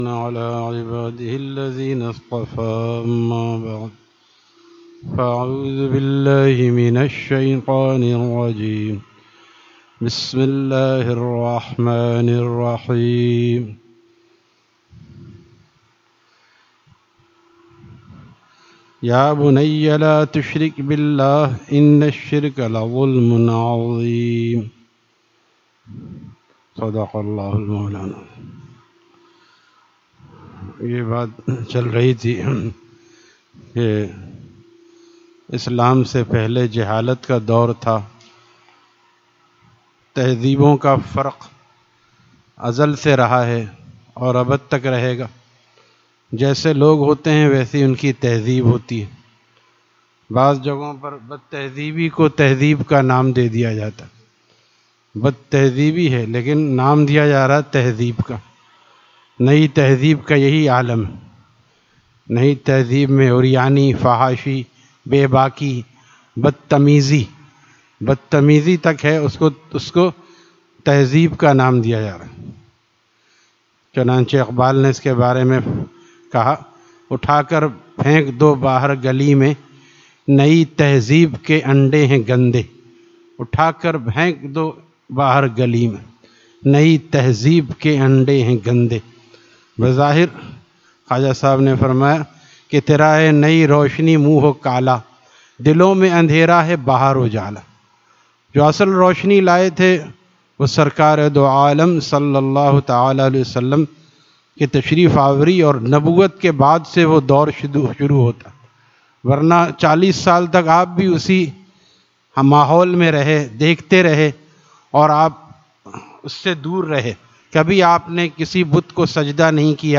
Friday Markazi Bayan at Jama Masjid Gulzar e Muhammadi, Khanqah Gulzar e Akhter, Sec 4D, Surjani Town